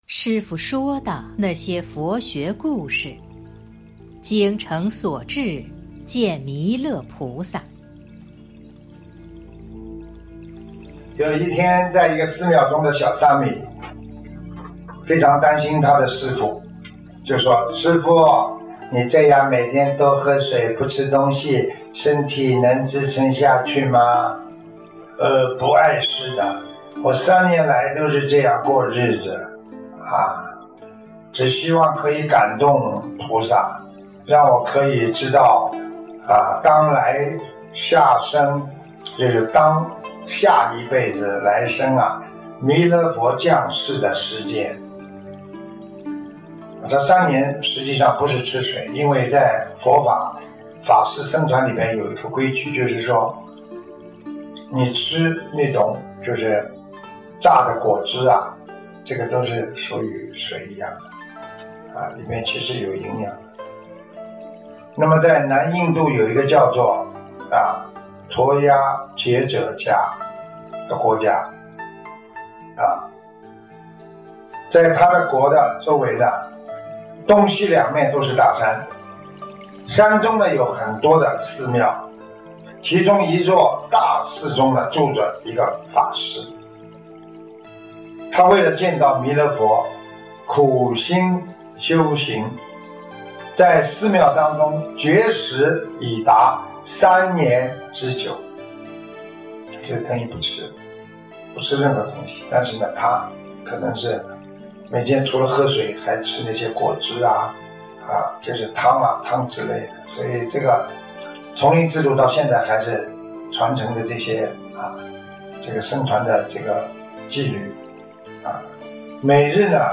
音频：《精诚所至见弥勒菩萨》师父说的那些佛陀故事！（后附师父解说）2020年02月05日【师父原声音】